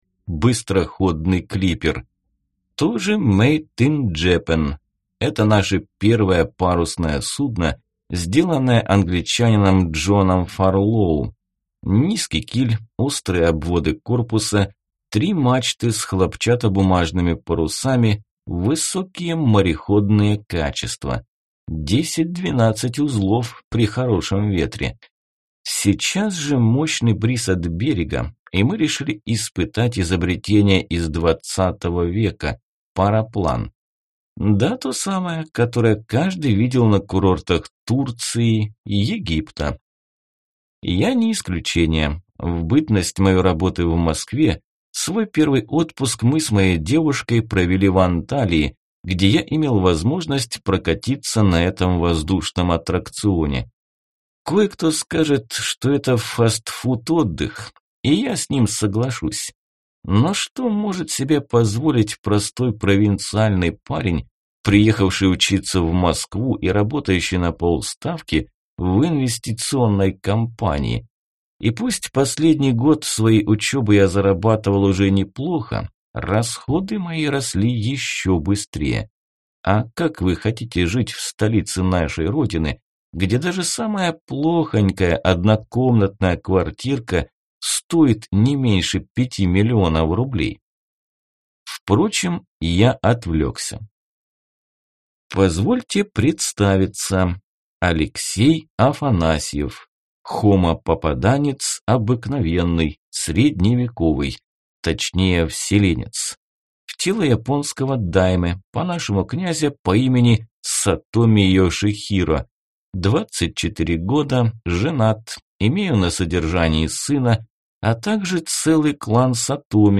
Аудиокнига Микадо. Император из будущего | Библиотека аудиокниг